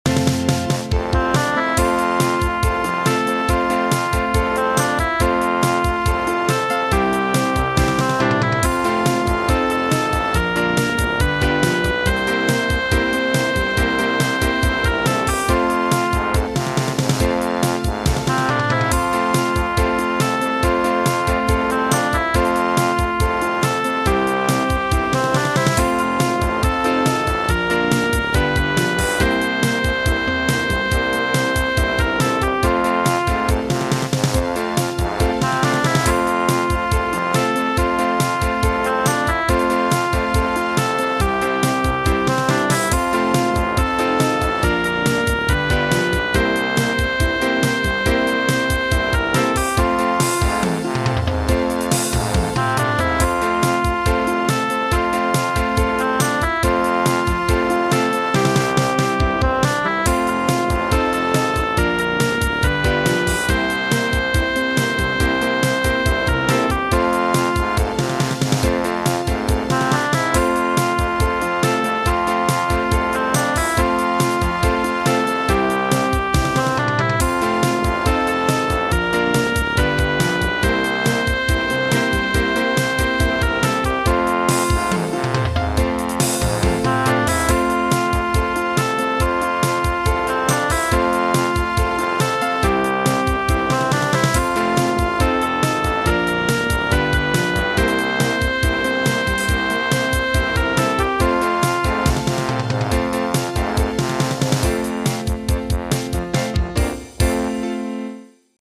Some kids have fun with it here.